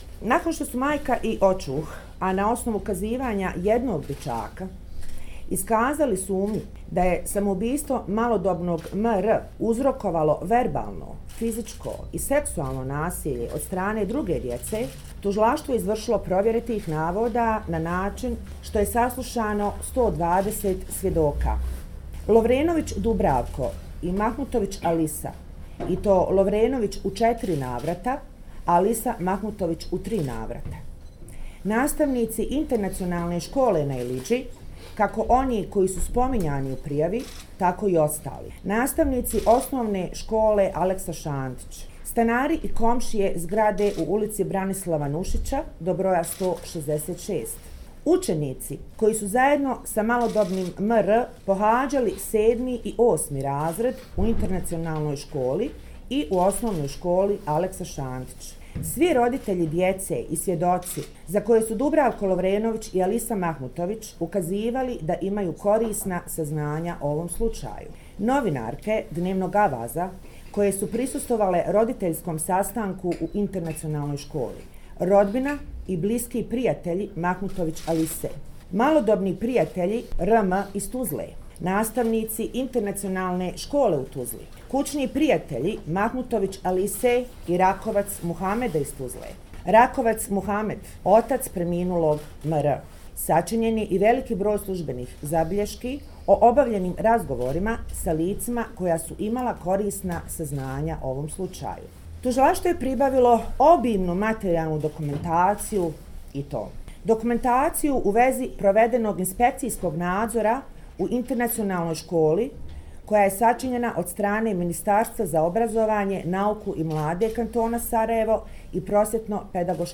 Izjava glavne tužiteljice Dalide Burzić